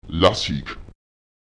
Lautsprecher lasek [Èlasek] schreiben (Schriftzeichen benutzen)